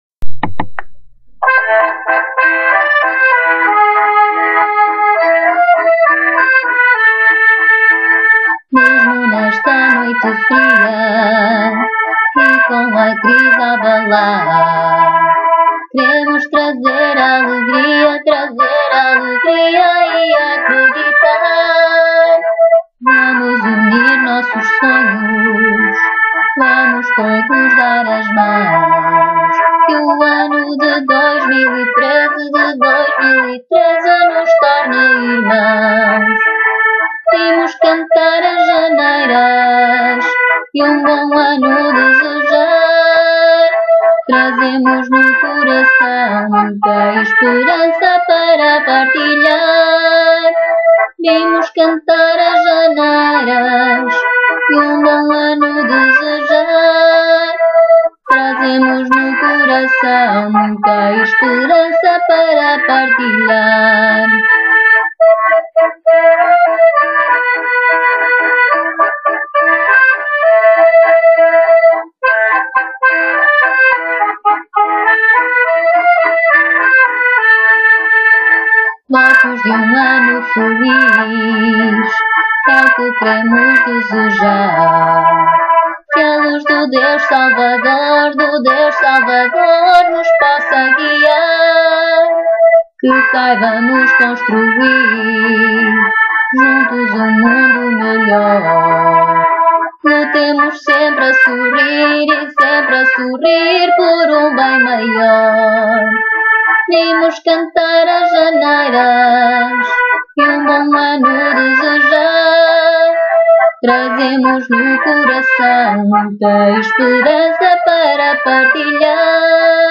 No dia 20 de Janeiro de 2013, participamos no 26º encontro de cantadores de janeiras da câmera municipal de Marco de Canaveses.
Janeiras - Com Voz.wma